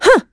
Valance-Vox_Attack6.wav